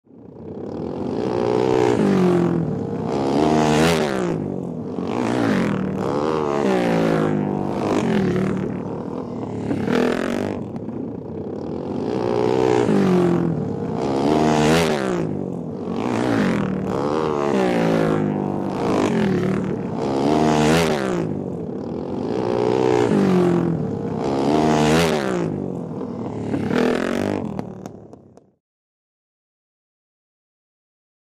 Motorcycle; Several Four Stroke Dirt Bike Bys.